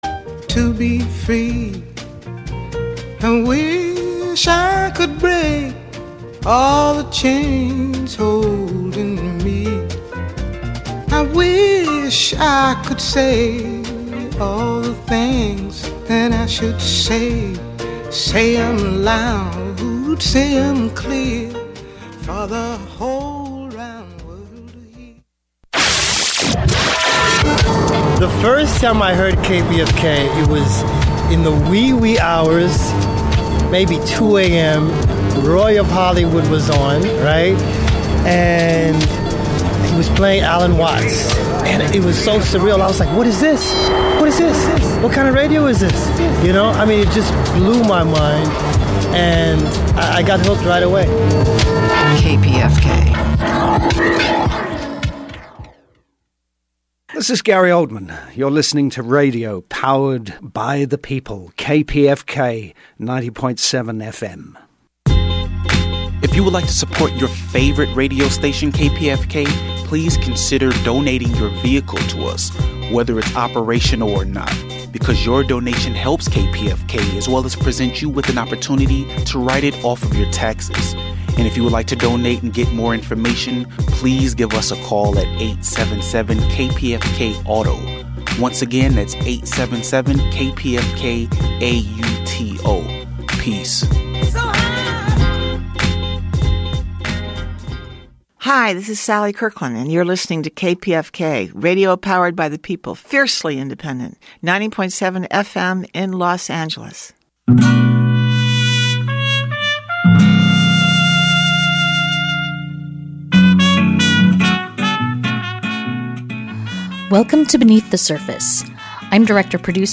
Beneath the Surface features interviews with leading thinkers and activists on the important issues of the day, with a focus on deeper analysis.